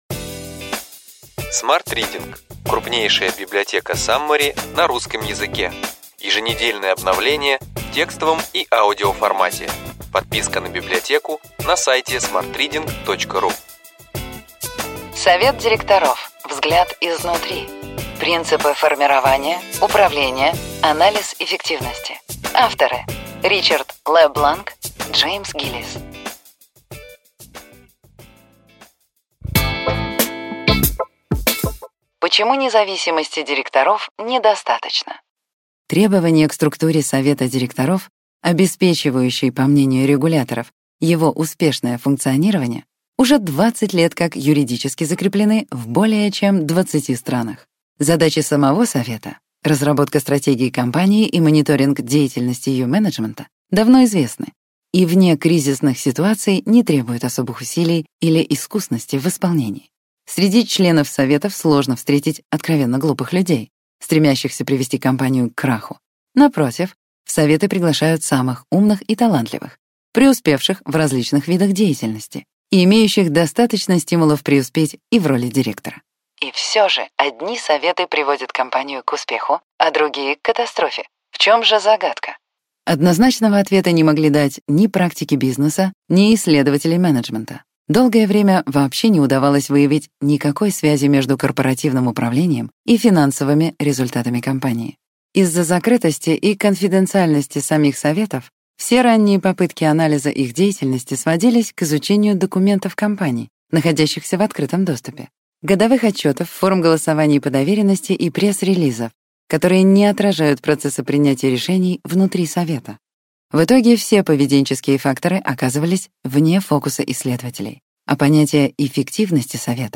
Аудиокнига Ключевые идеи книги: Совет директоров – взгляд изнутри.